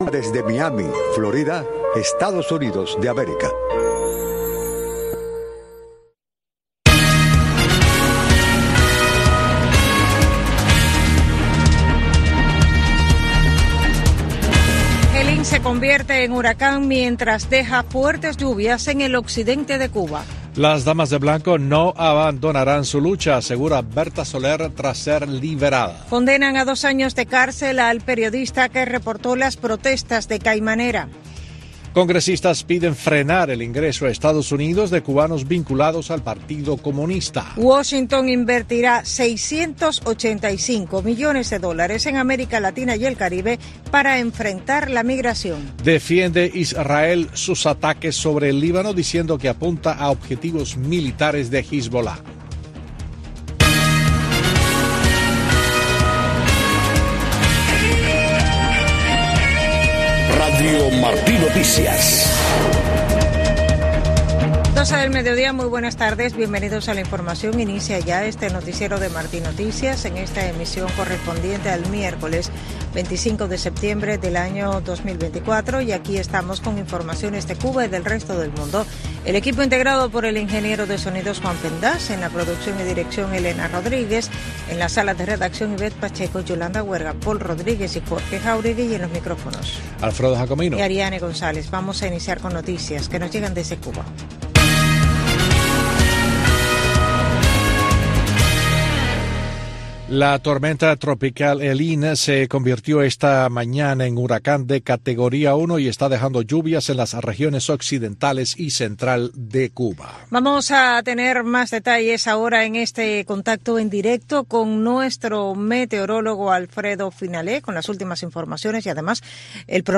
Noticiero Radio Martí presenta los hechos que hacen noticia en Cuba y el mundo.